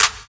112 CLAP.wav